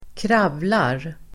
Ladda ner uttalet
kravla verb, crawl Grammatikkommentar: A & Uttal: [²kr'av:lar el. ²kr'a:vlar] Böjningar: kravlade, kravlat, kravla, kravlar Synonymer: krypa, kräla, åla Definition: förflytta sig mödosamt med armar och ben